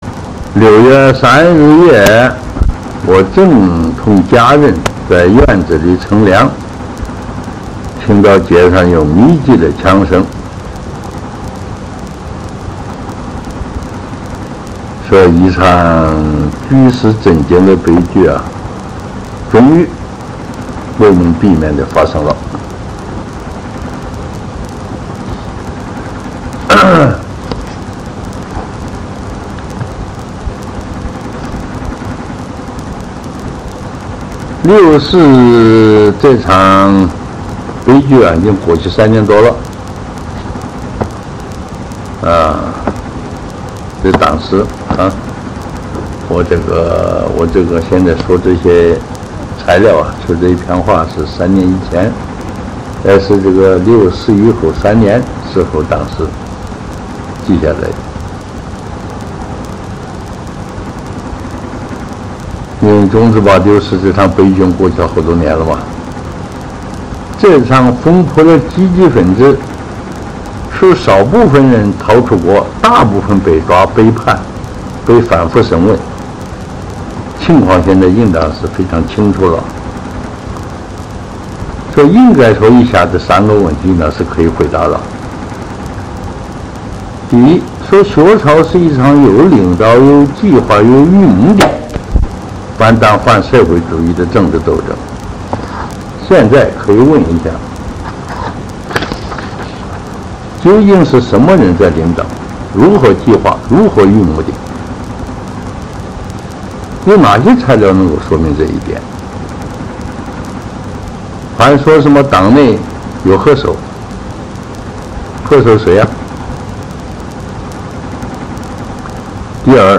赵紫阳录音回忆录《改革历程》节选